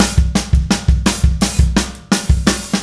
Indie Pop Beat 02 Fill.wav